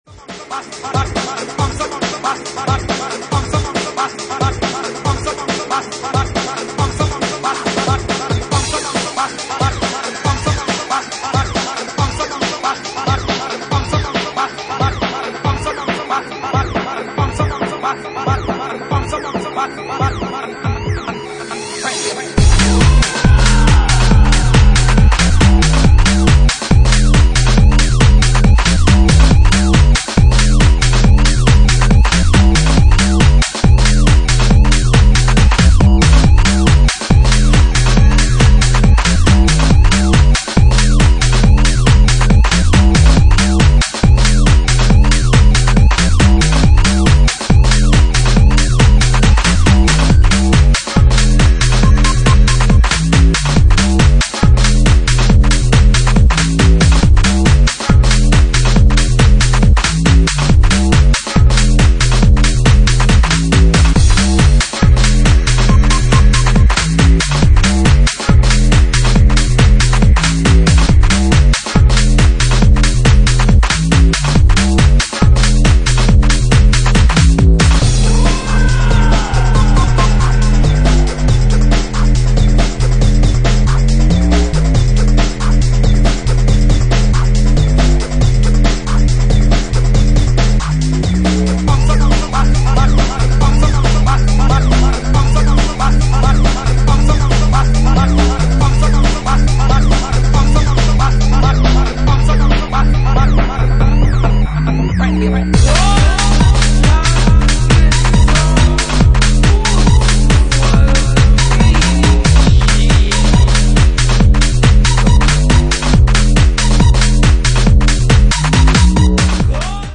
Genre:Bassline House
Bassline House at 139 bpm